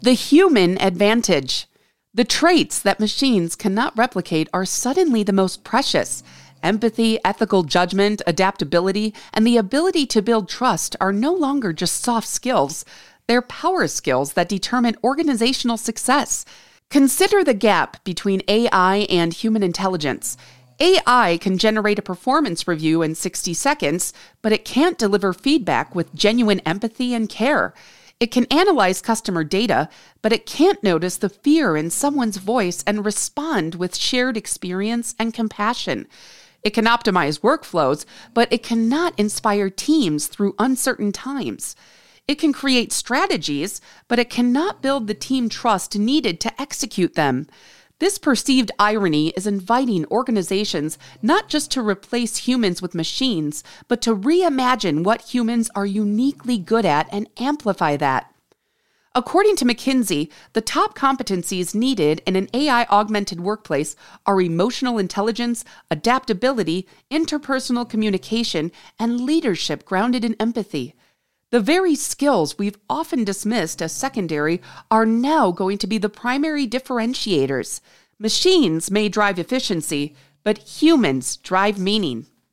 Audiobook Demo
Middle Aged